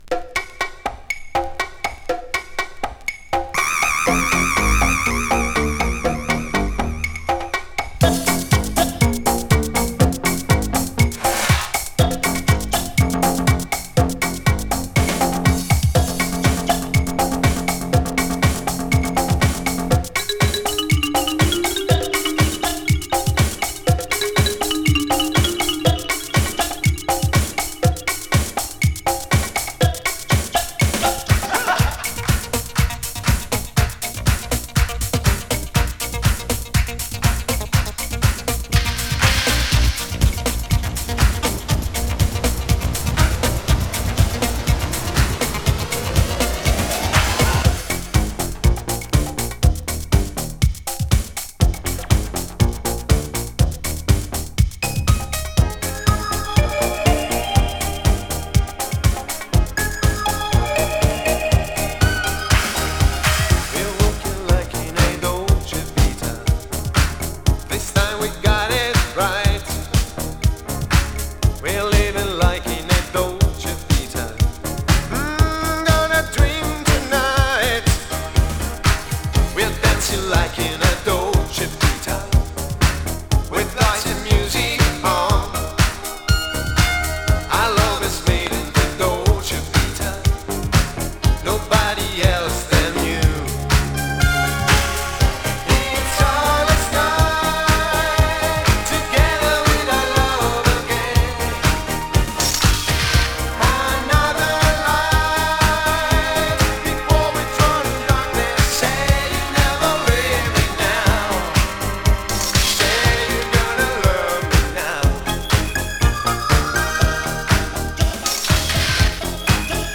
Italo Disco Classic House Remix!
【ITALO DISCO】